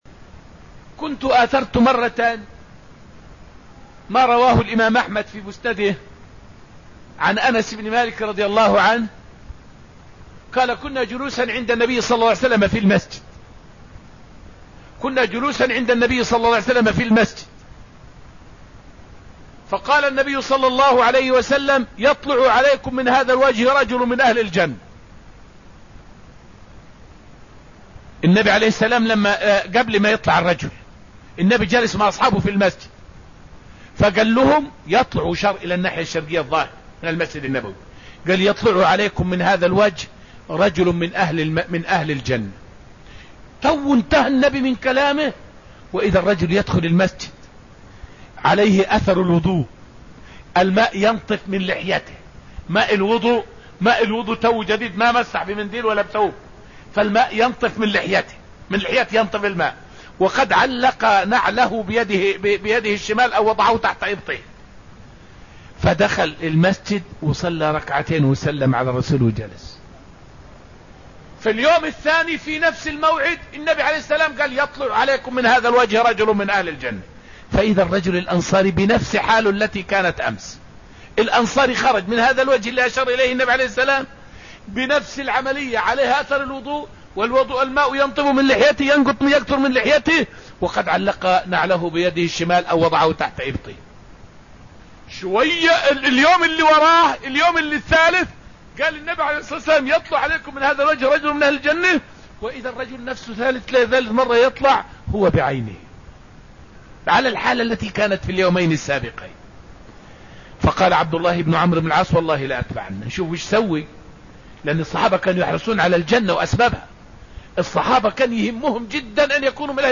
فائدة من الدرس السابع من دروس تفسير سورة الحشر والتي ألقيت في المسجد النبوي الشريف حول ثواب سلامة الصدر على المسلمين.